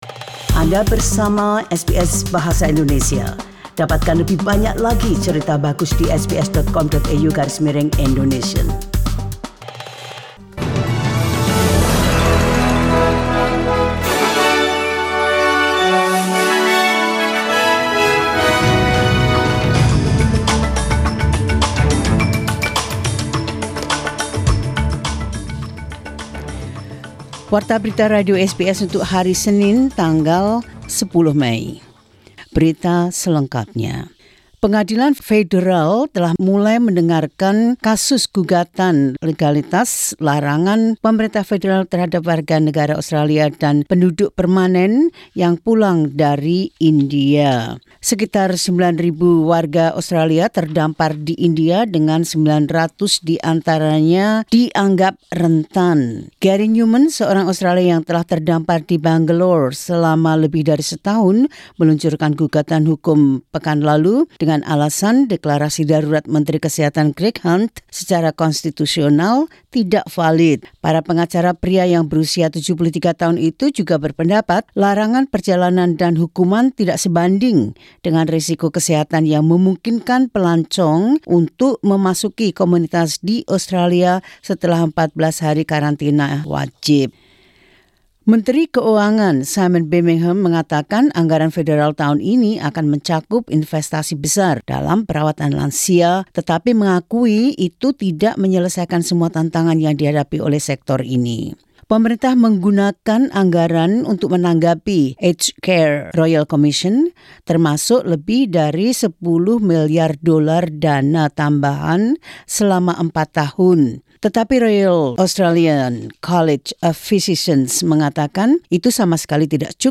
Warta Berita Radio SBS Program Bahasa Indonesia – 10 Mei 2021.